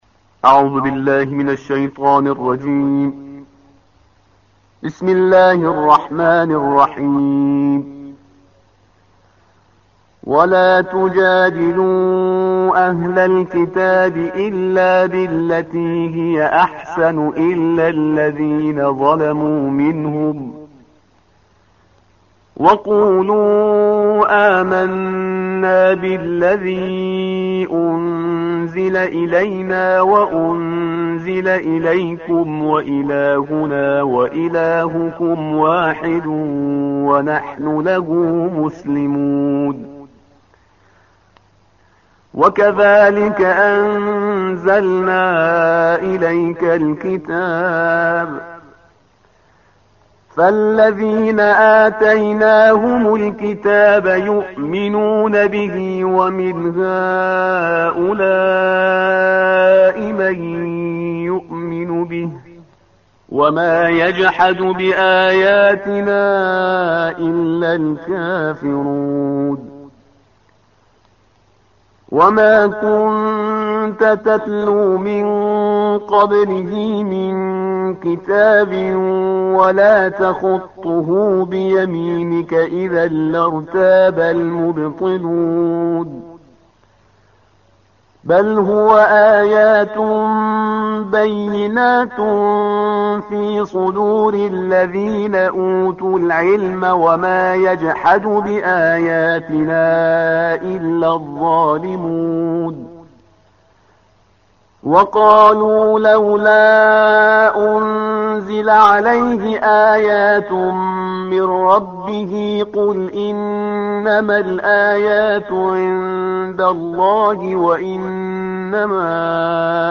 ترتیل کل قرآن